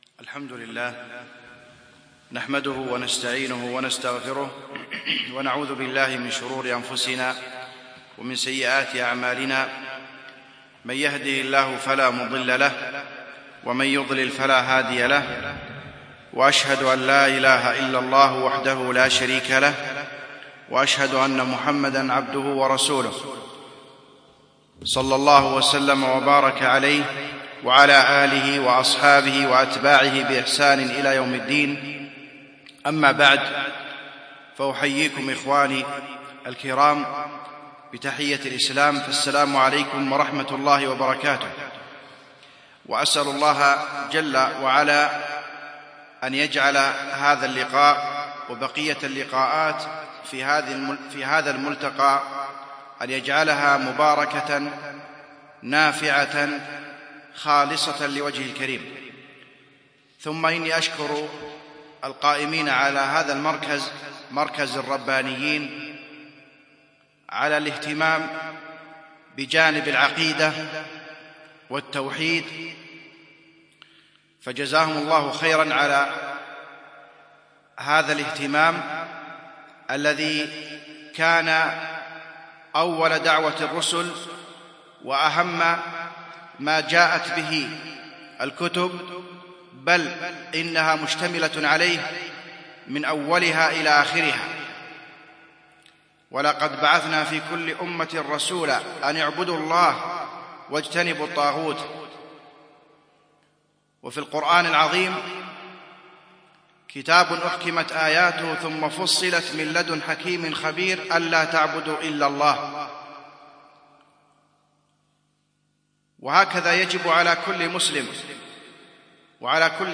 يوم الثلاثاء 27 جمادى الأخر 1437 الموافق 5 4 2016 في مسجد كليب مضحي العارضية